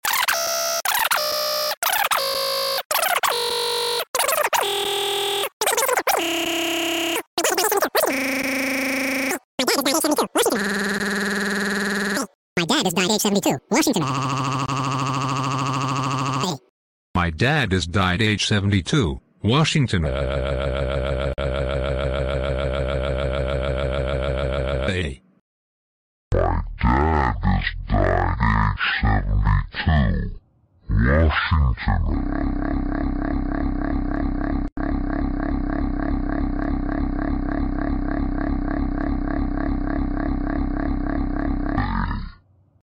*insert goofy ahh crying sound*